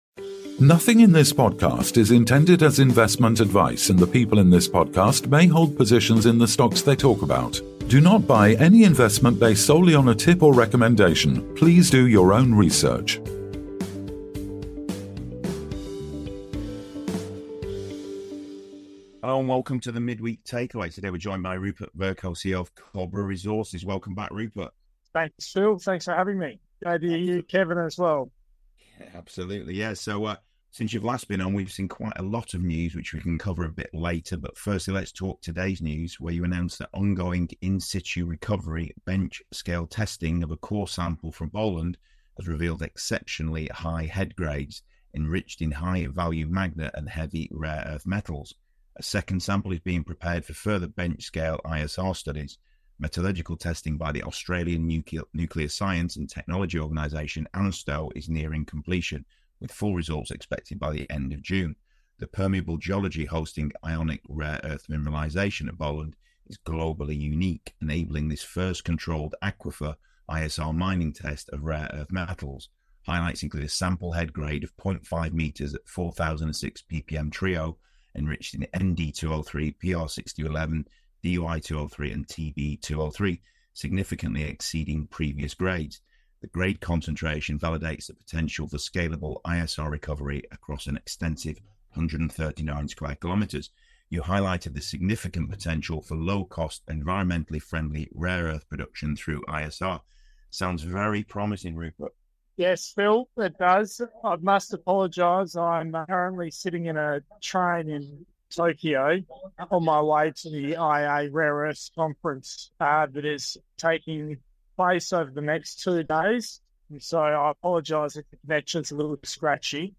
Join us for an insightful discussion on rare earth mineralization, innovative ISR mining methods, and the promising future of Cobra Resources in the global mining industry.